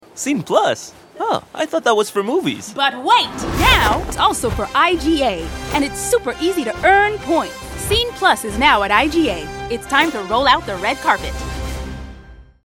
Commercial (IGA) - EN